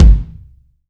Kicks
KICK.1.NEPT.wav